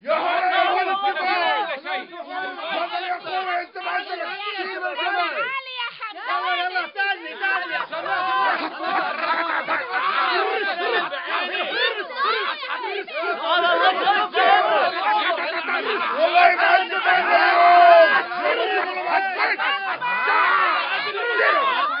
Арабы спорят на рынке (мужские и женские голоса)